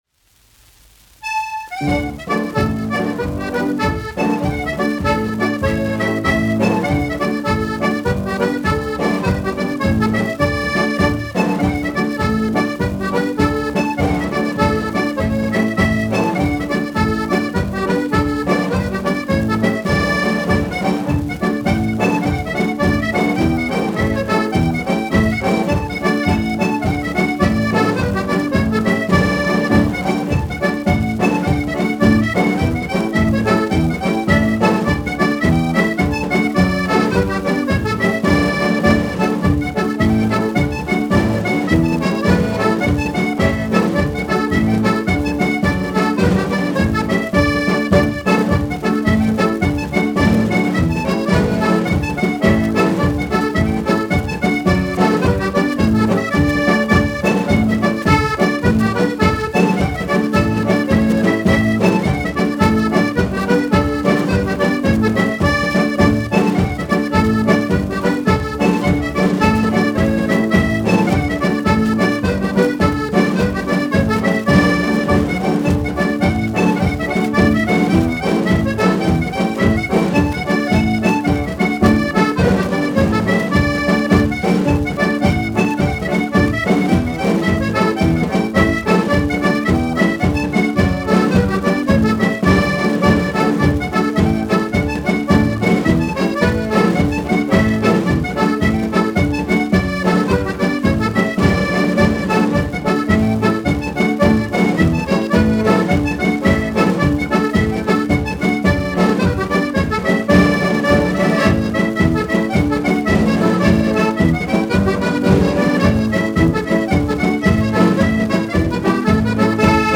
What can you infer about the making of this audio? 1 10 inch 78rpm shellac disc